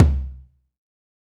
BR Tom Mid.WAV